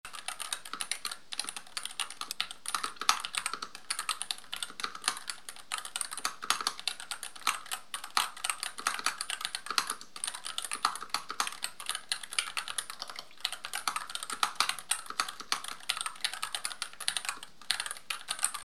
Użytkowanie: pisanie, gry i dźwięk.
Dzięki zastosowaniu rozbudowanej izolacji wewnętrznej oraz naturalnych właściwości przełączników, klawiatura charakteryzuje się: cichym, niskotonowym dźwiękiem pracy, brakiem metalicznego pogłosu, typowego dla tańszych modeli, miękkim, „puszystym” feelingiem przypominającym topowe klawiatury custom.
Dzwiek-Epomaker-HE65-Mag.mp3